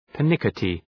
{pər’nıkətı}